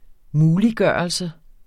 Udtale [ -ˌgɶˀʌlsə ]